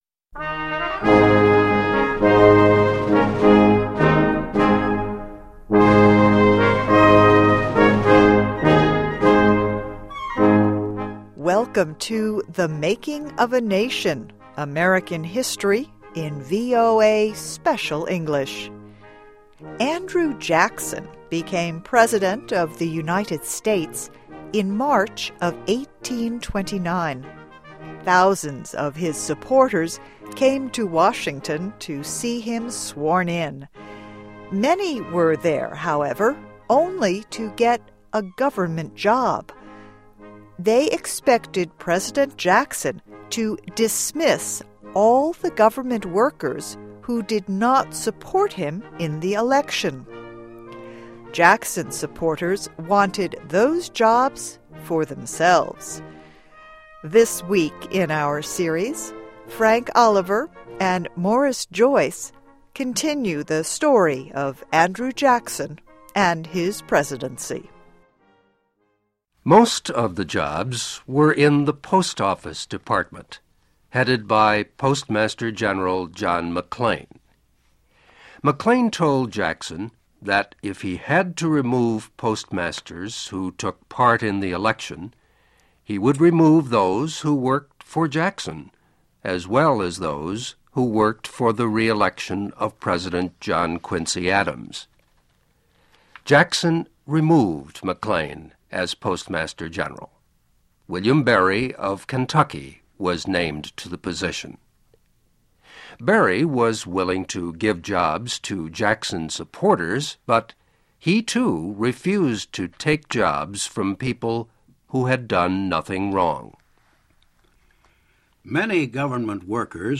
American History: Split Divides Jackson, Vice President Calhoun (VOA Special English 2008-10-29)
Listen and Read Along - Text with Audio - For ESL Students - For Learning English